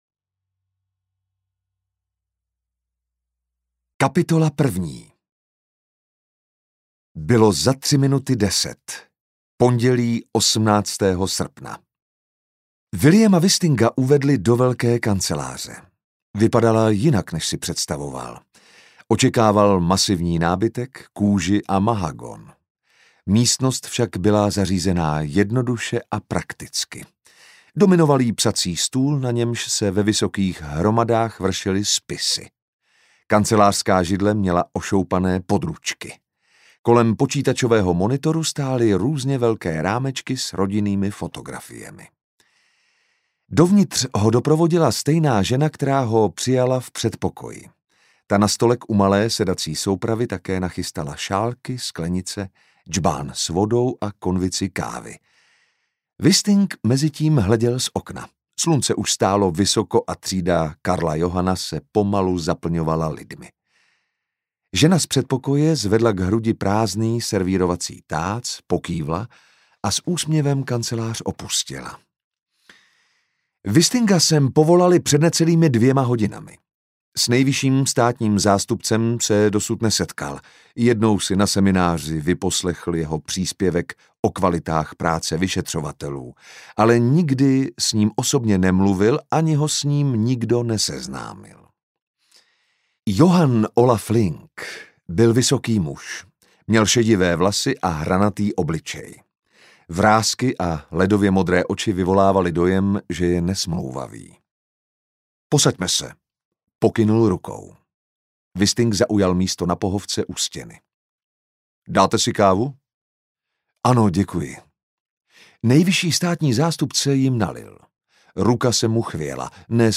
Zadní pokoj audiokniha
Ukázka z knihy